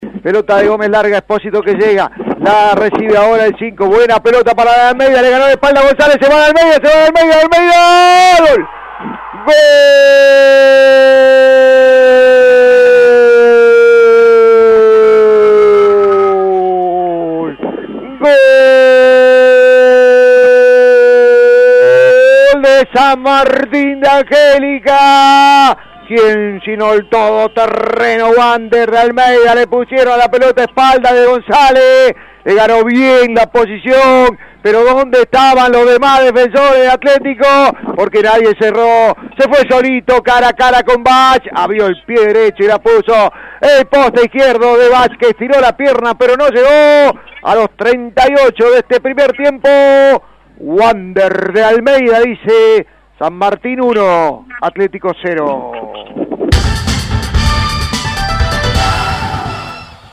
GOLES: